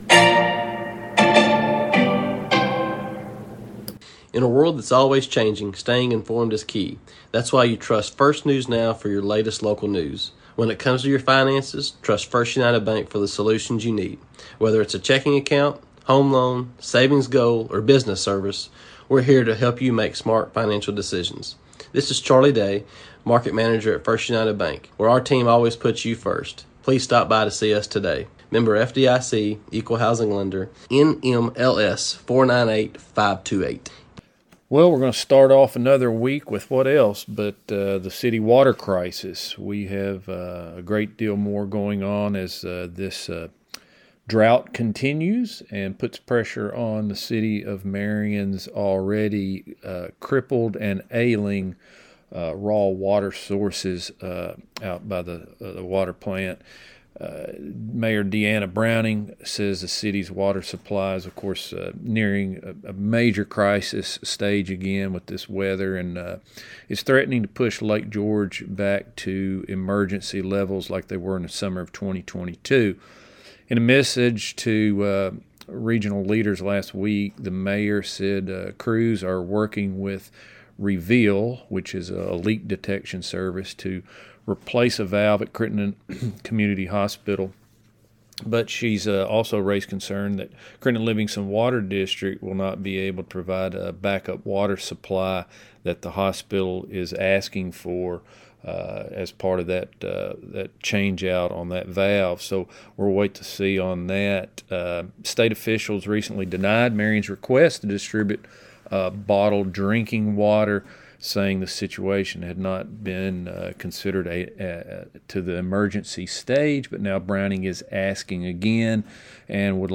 News | Sports | Local Interviews